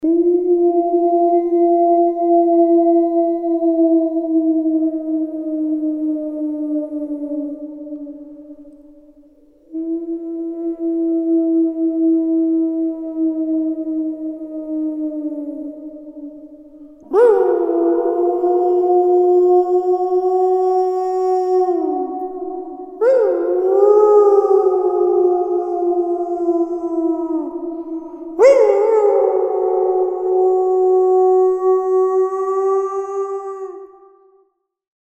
Hiệu ứng âm thanh Kinh Dị